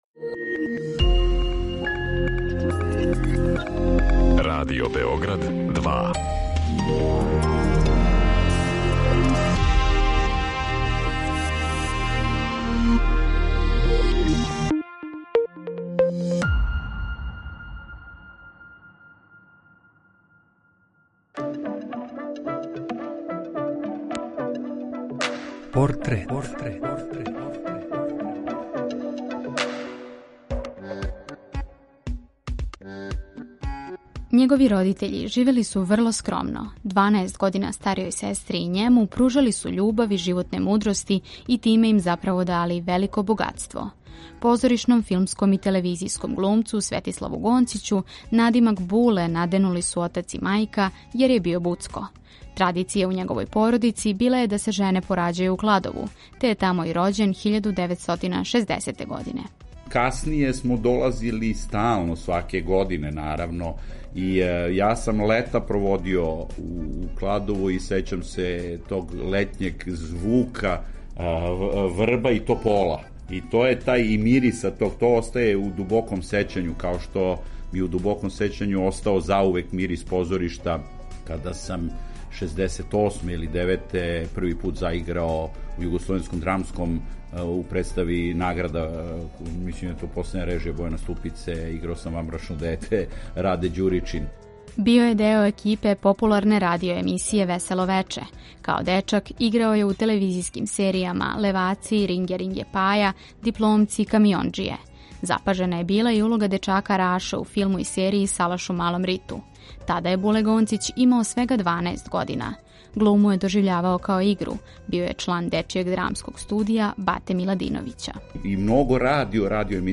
интервјуа, изјава, анкета и документраног материјала